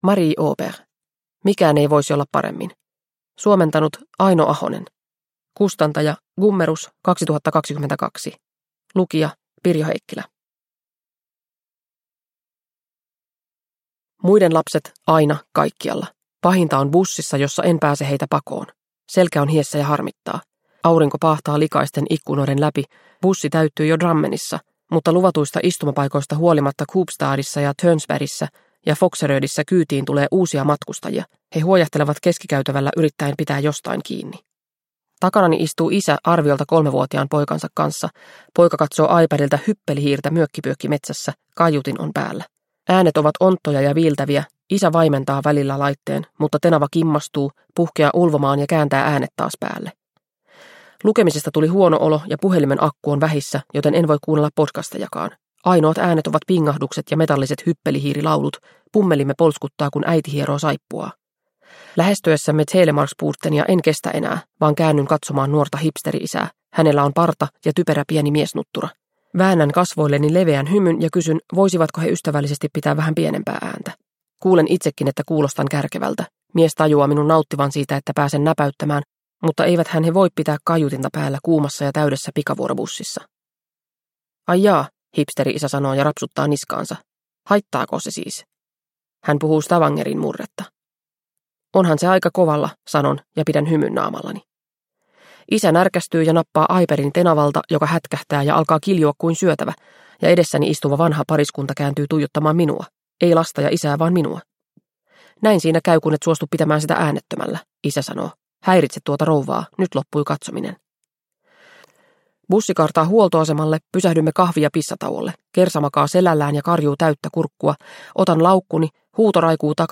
Mikään ei voisi olla paremmin – Ljudbok – Laddas ner